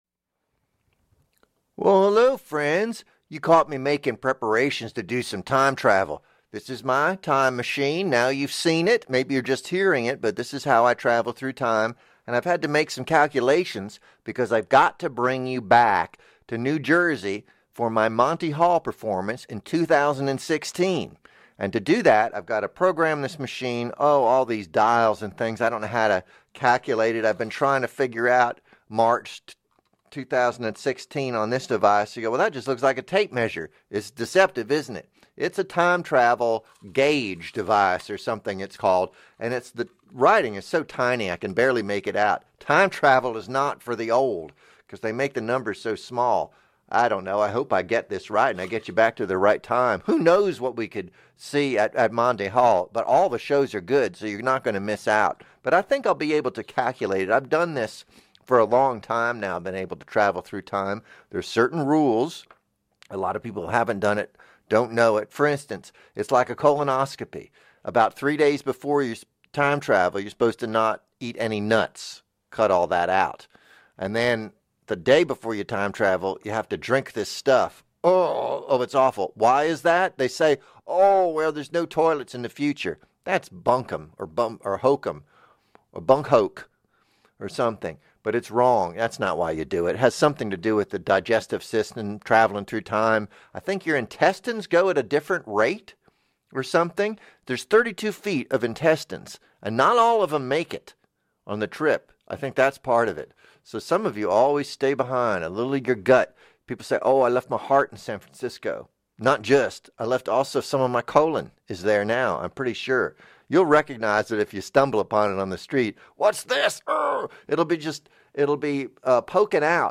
Live performance from March 18, 2016, WFMU's Monty Hall. from Apr 14, 2016